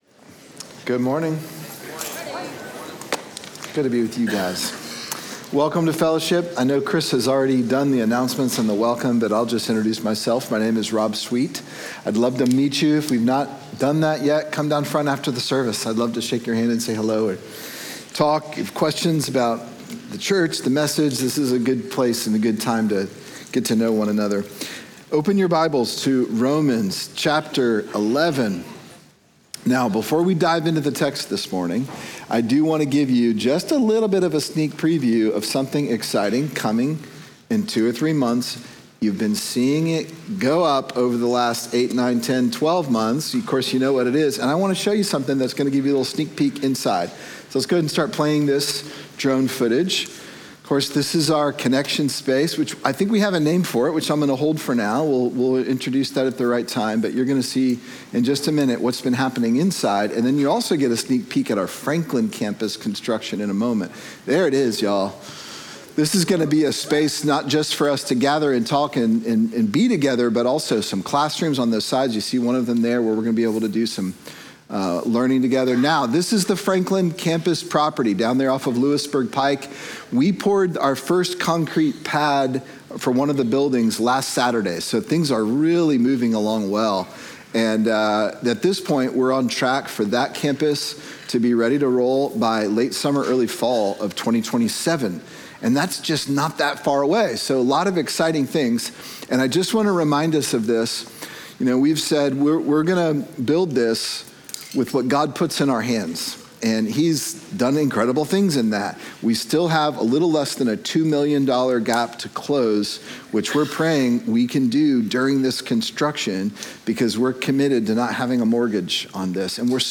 Sermon Unfathomable: Romans 9-11